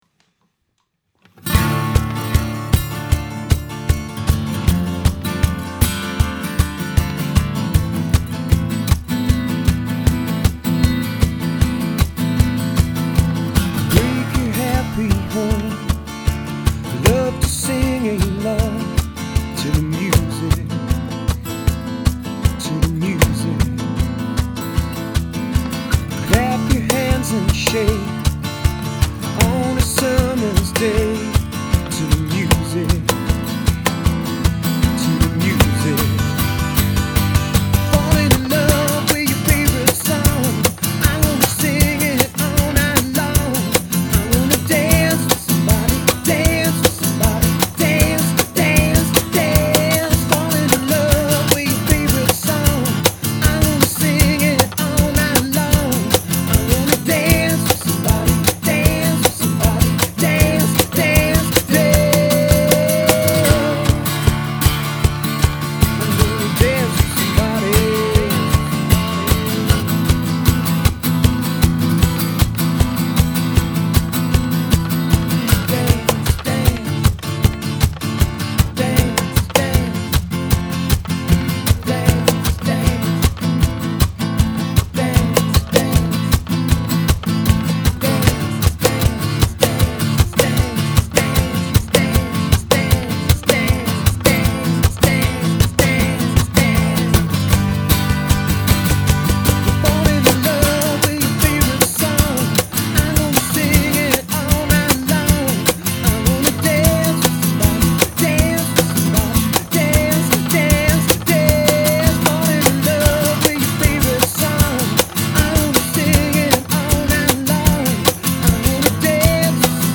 pop'n'roll á la boss hoss
That’s what we call: POP’N’ROLL !